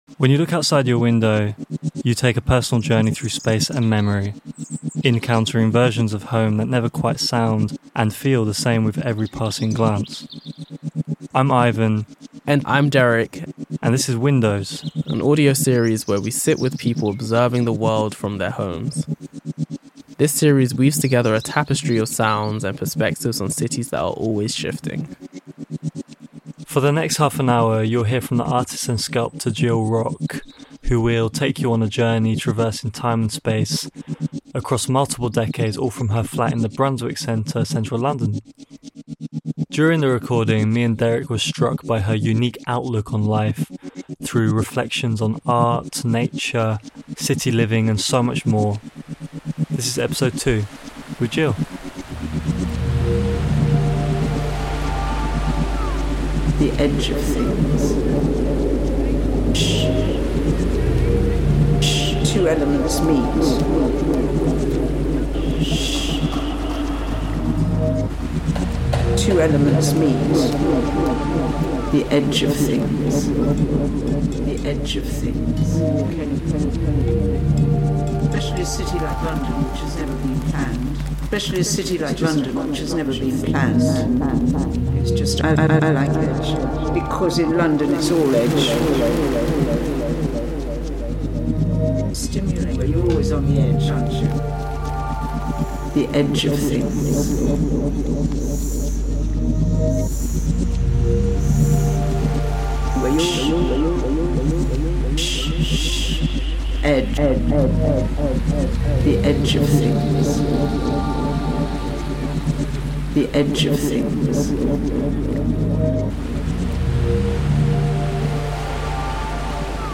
Featuring experimental sound design that reflects the processes of art-making and city-wandering